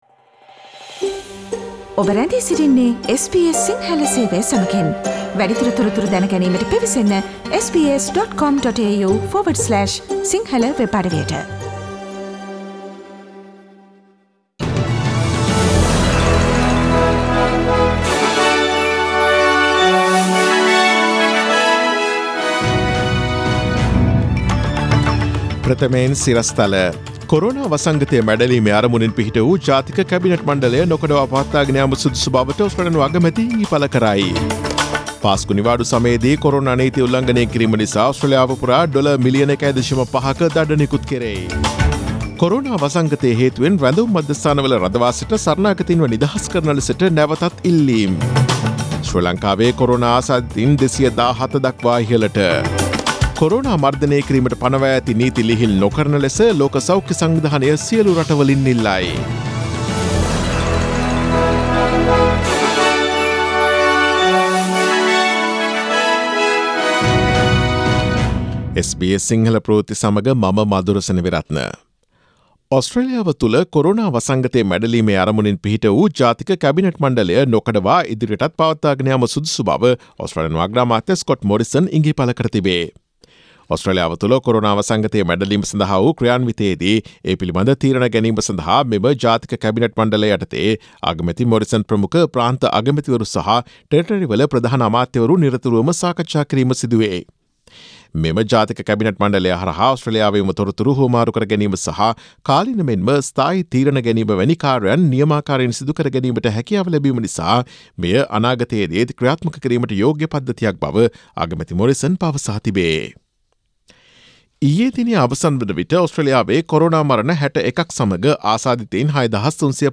Daily News bulletin of SBS Sinhala Service: Tuesday 14 April 2020